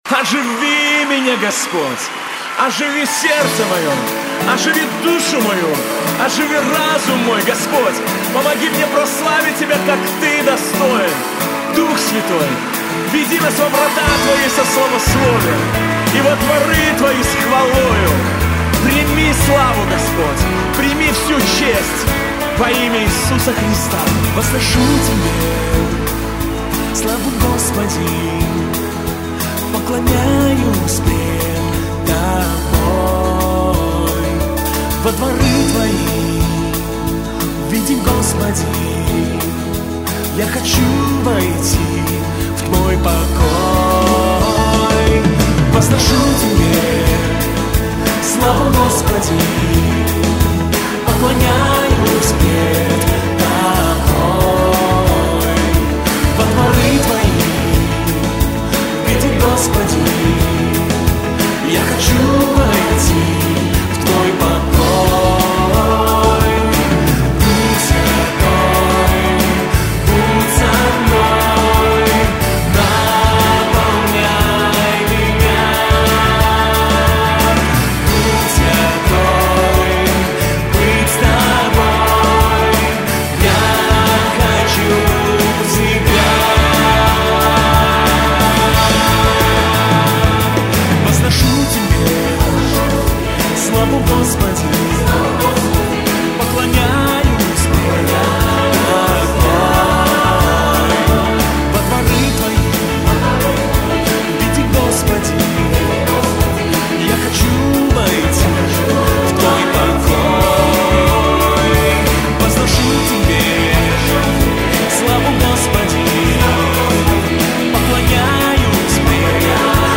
песня
1783 просмотра 2685 прослушиваний 165 скачиваний BPM: 90